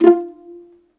VIOLINP .1-L.wav